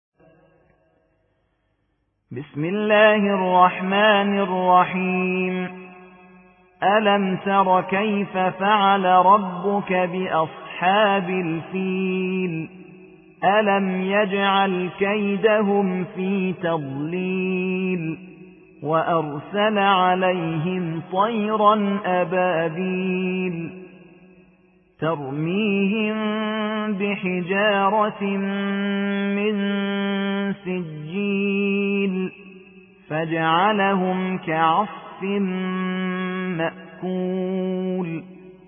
105. سورة الفيل / القارئ
القرآن الكريم